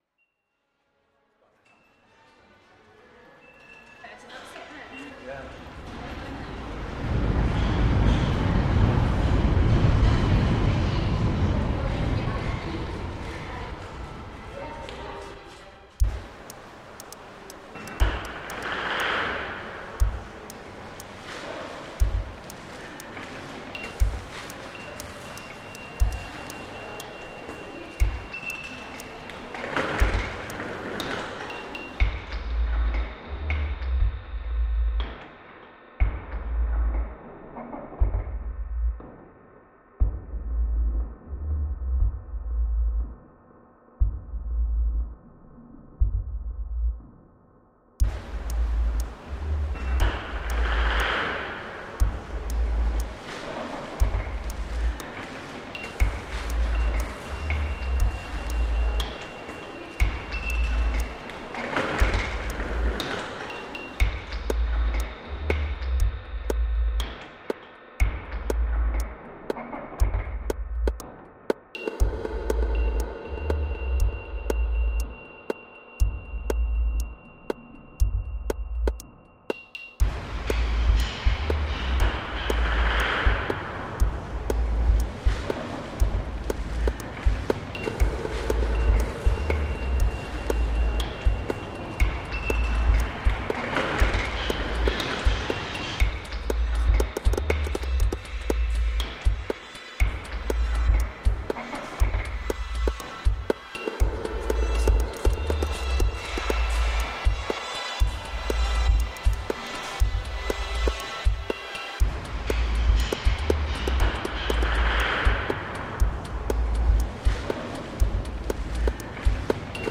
"First, I selected the "relevant" / favourite parts of the recording to play around with it. I put them in separate channels of my DAW for cutting, looping , eq-ing, ... Except one hihat, all sounds are from the original recording."